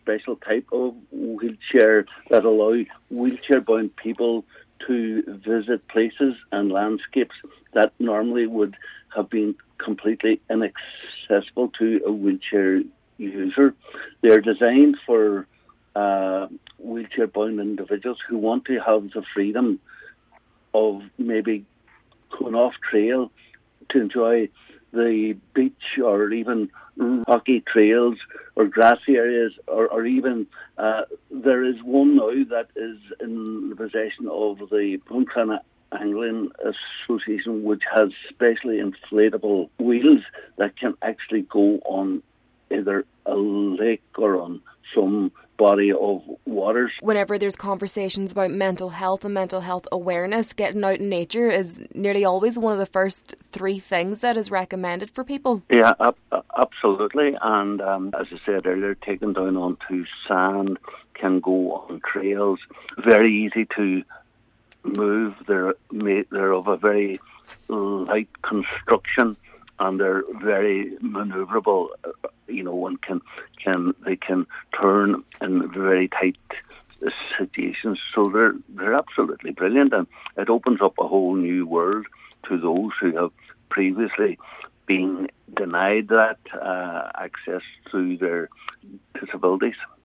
Cathaoirleach of Inishowen Cllr Terry Crossan says it opens a new realm of possibilities to those who may have bene restricted in the past: